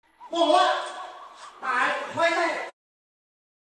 Thể loại: Câu nói Viral Việt Nam
Description: Đây là kiểu sound effect vui nhộn, mang tính giải trí cao, cực kỳ thích hợp cho những ai thích lồng tiếng video, chèn âm thanh nền hay thêm tiếng động phụ để tạo điểm nhấn.